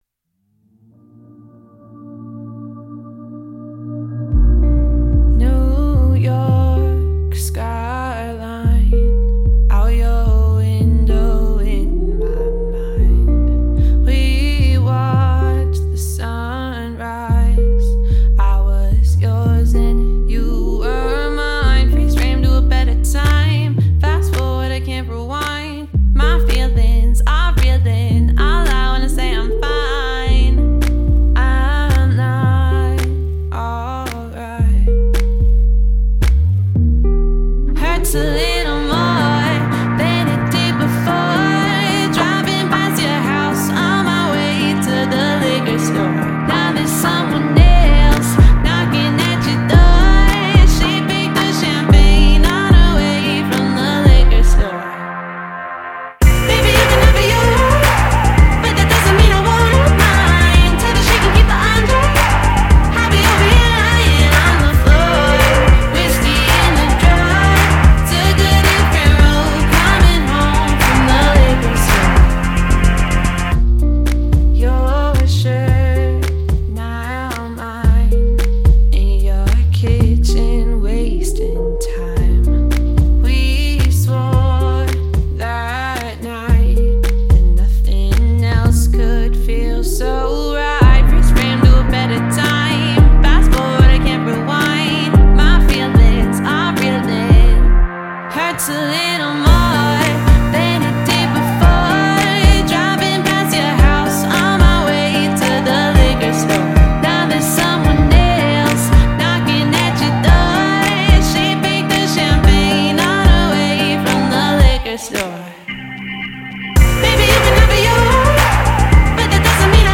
# Pop